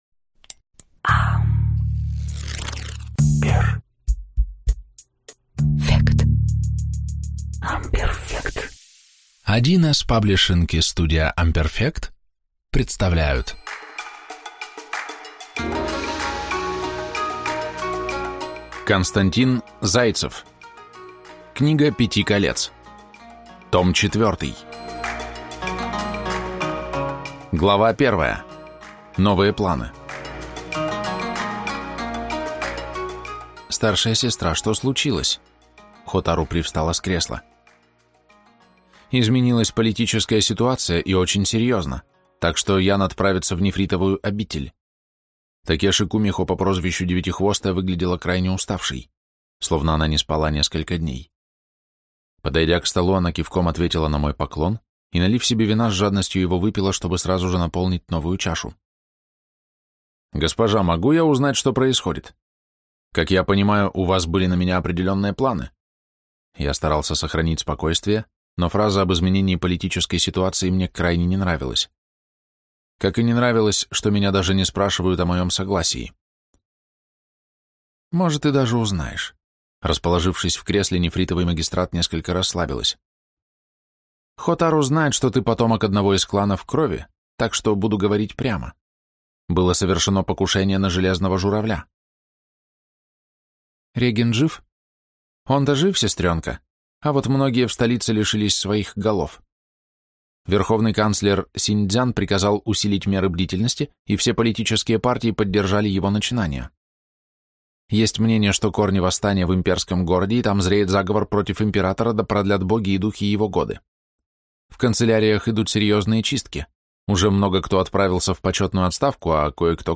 Слушать аудиокнигу Книга пяти колец.